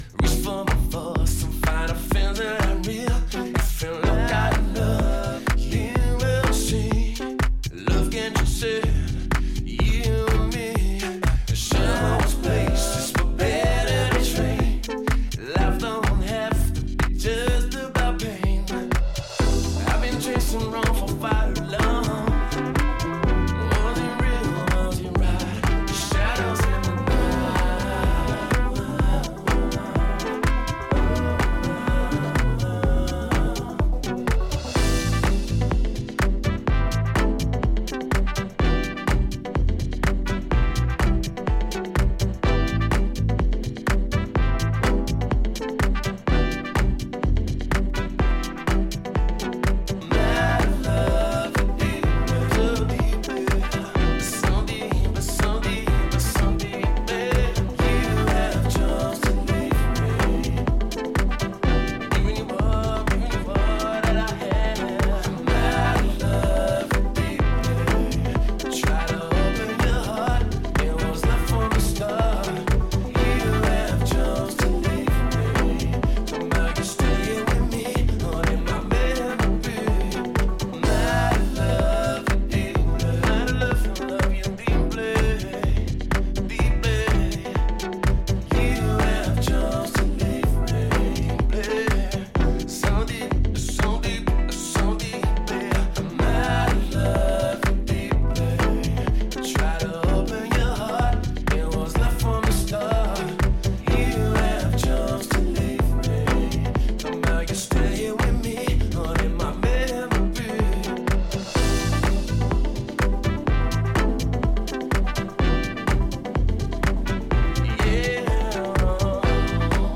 こちらもディープでソウルフルなフィーリングが光る秀逸な仕上がりとなっています